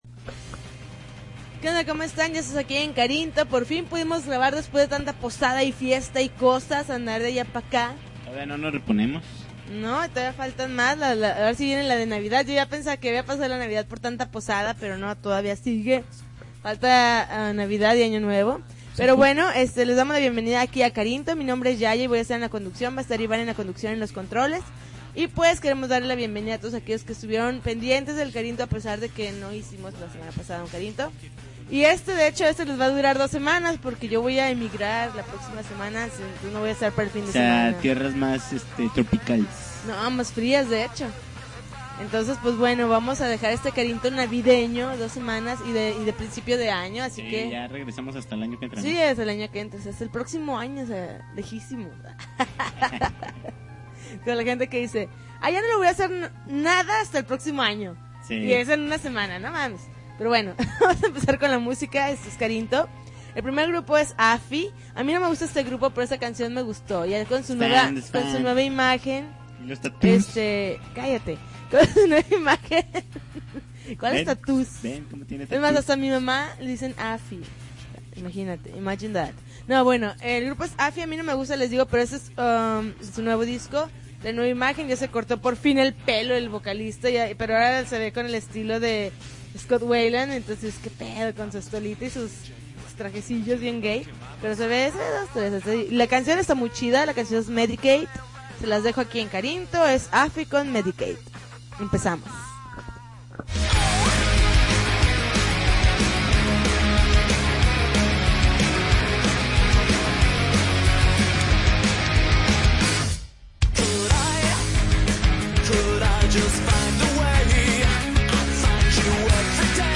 December 20, 2009Podcast, Punk Rock Alternativo